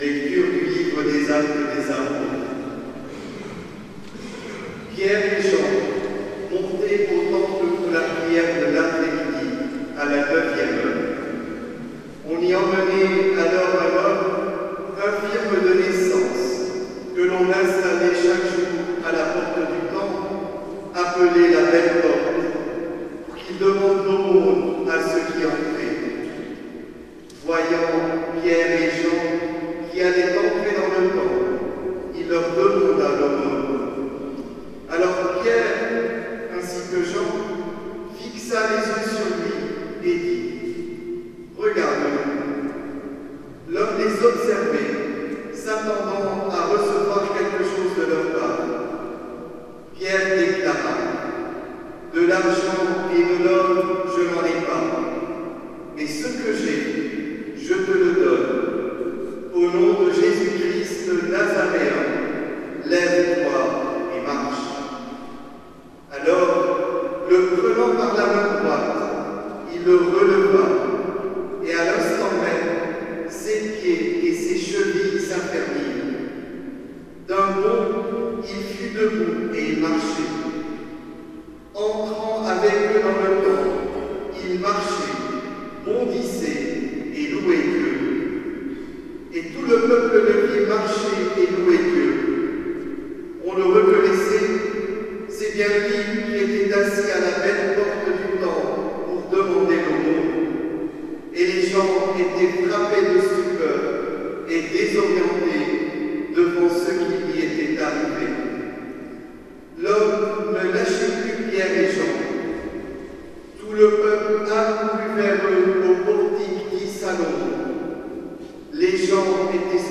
Soirée de prières, de consolation et de guérison présidée par Mgr Aupetit Saint Louis le 9 avril 2025
06-Lecture-du-livre-de-lApocalypse.mp3